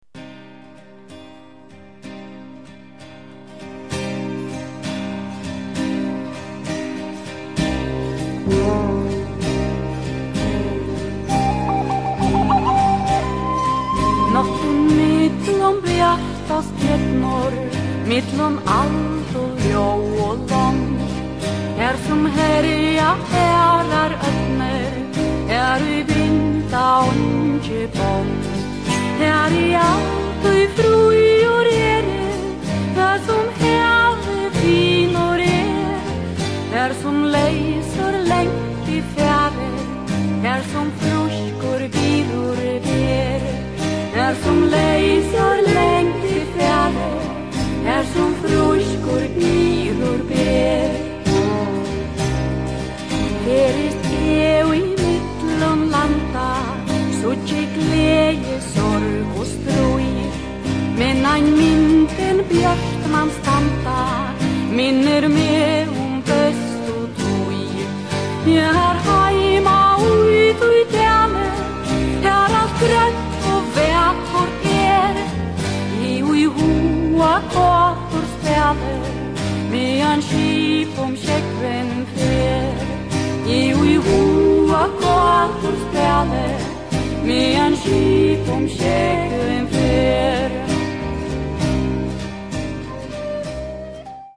recording of a song in a mystery language.